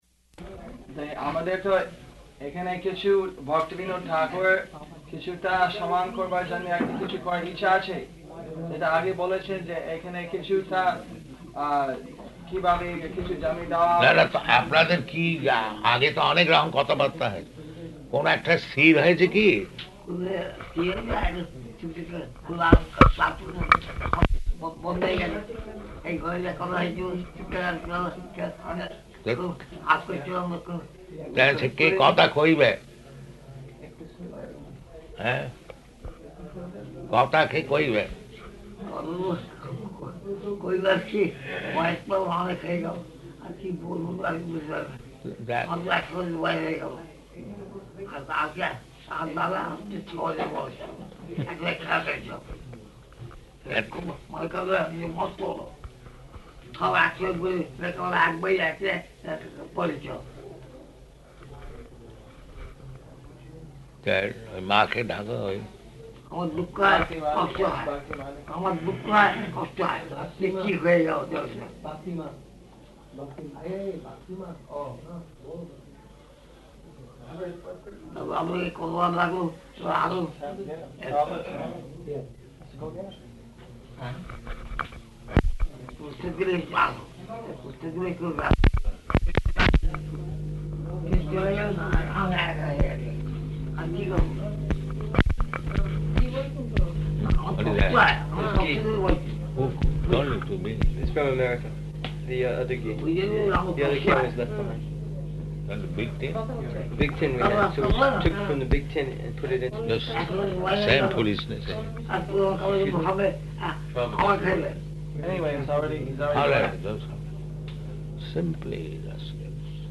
Room Conversation in Bengali
-- Type: Conversation Dated: March 22nd 1976 Location: Māyāpur Audio file